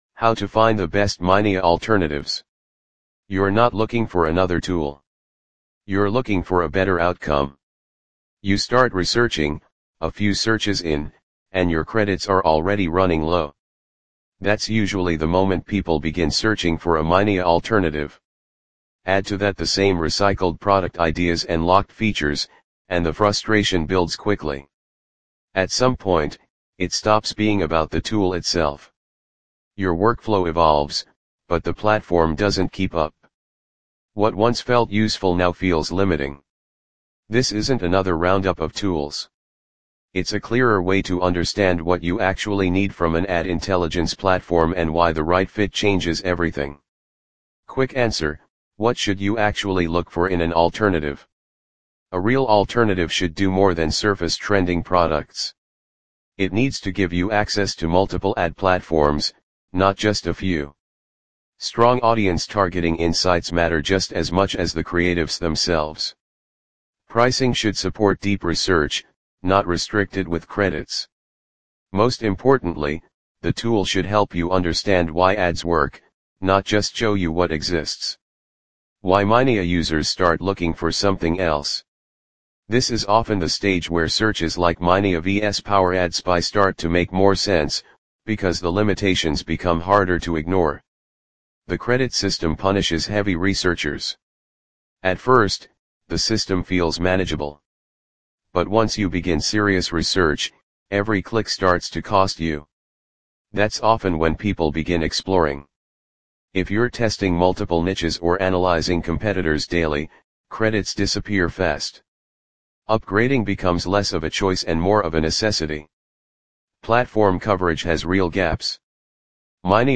Read Aloud!